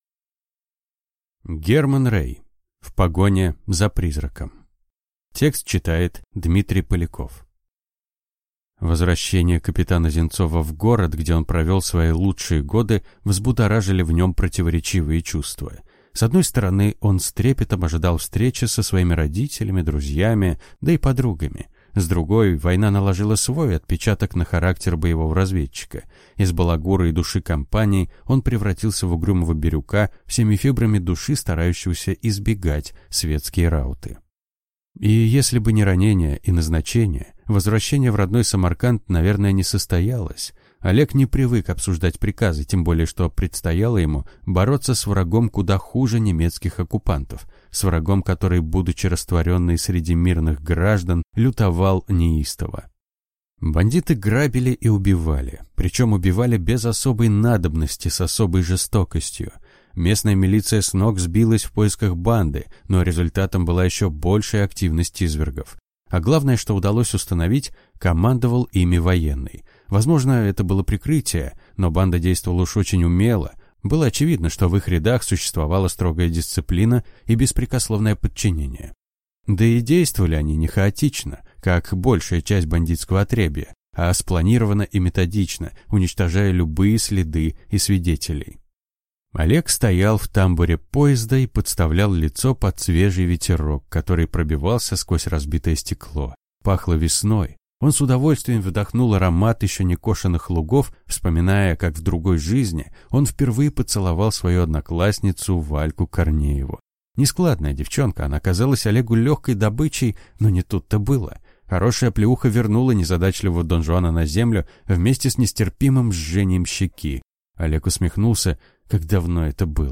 Аудиокнига В погоне за призраком | Библиотека аудиокниг